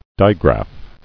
[di·graph]